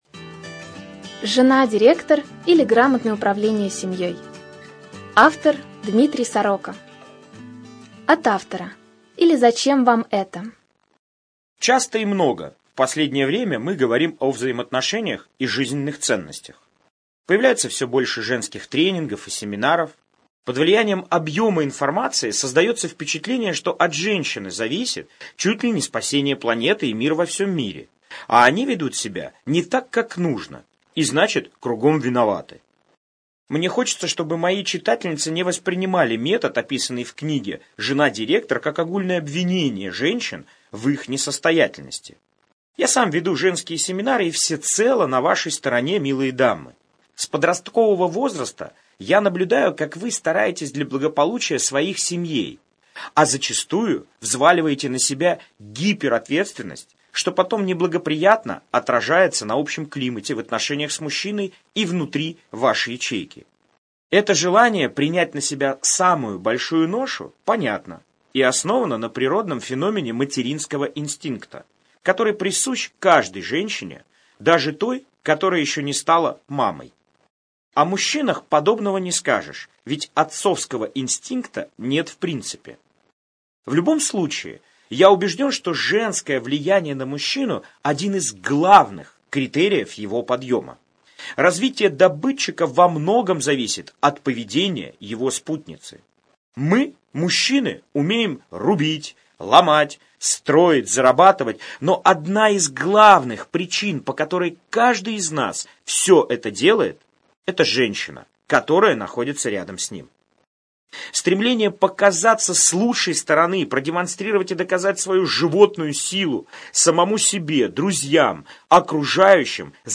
О том, как быть эффективной женой-директором и сделать так, чтобы компания (семья) вашего учредителя (мужа) процветала – слушайте в этой аудиокниге.